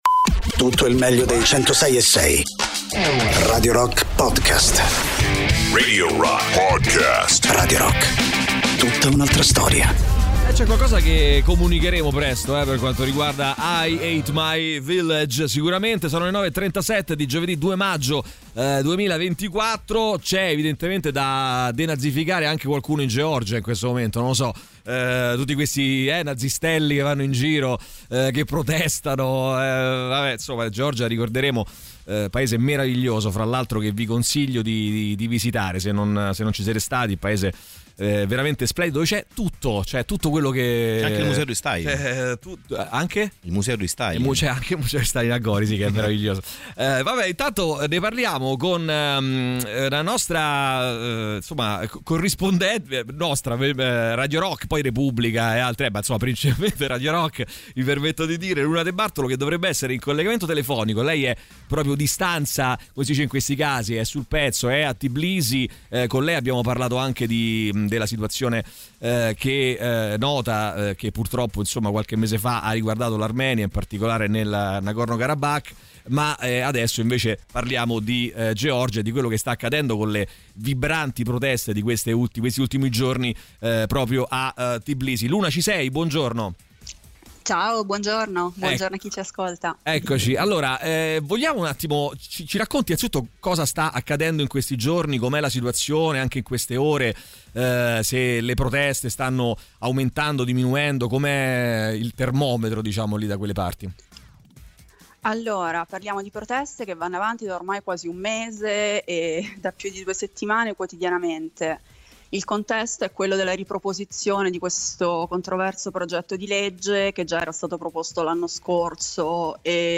Interviste
in collegamento telefonico